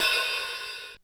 HiHatOp.wav